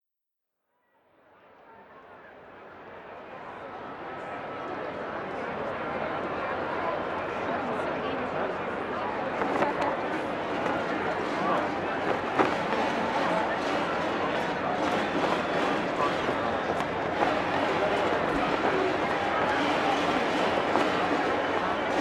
Здесь вы услышите гул мостовых, крики торговцев, звон трамваев и другие характерные шумы ушедших эпох.
Шум старого города: гул средневековой ярмарки